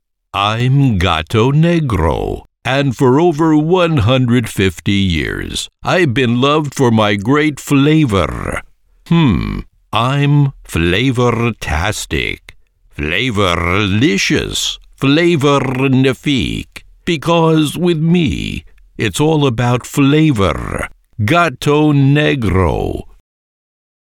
Anglais (Américain)
Commerciale, Profonde, Naturelle, Distinctive, Amicale